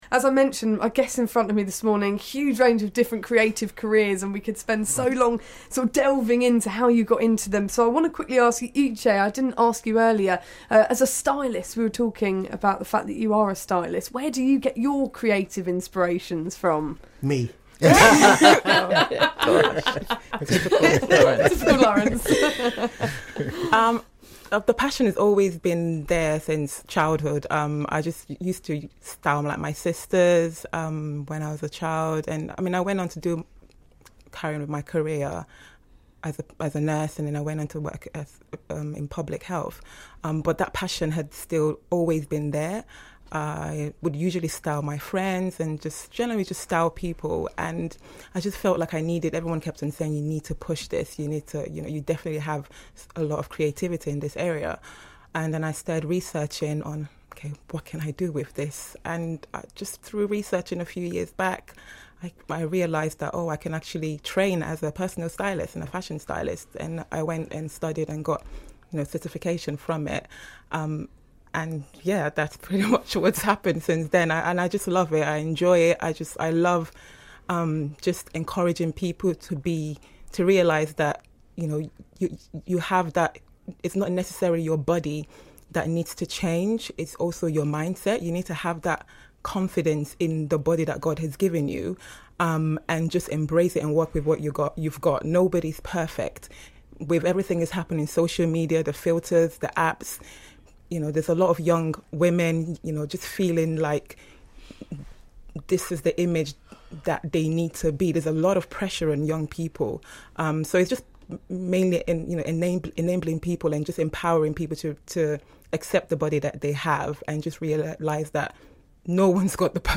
Featured on BBC Radio